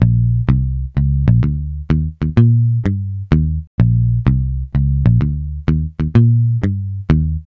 Swingerz 1 Bass-A#.wav